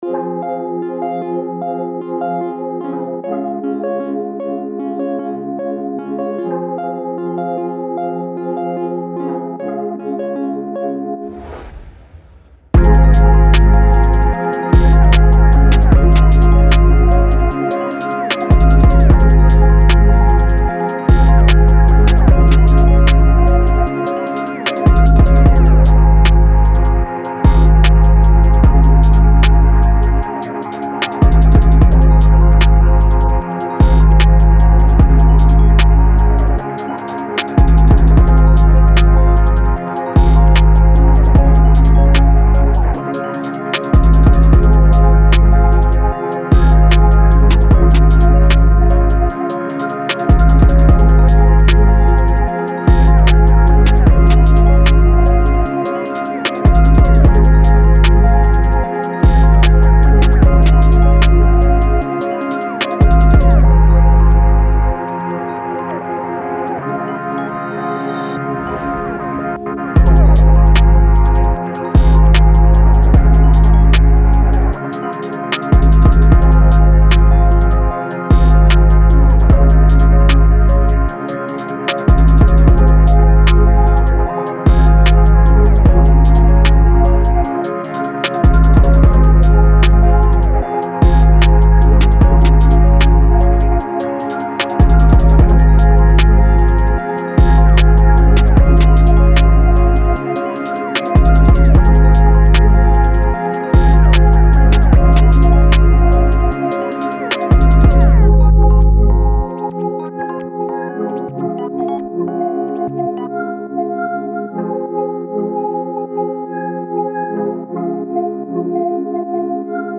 Cold, Dark, Energetic
Drum, Heavy Bass, Piano, Strings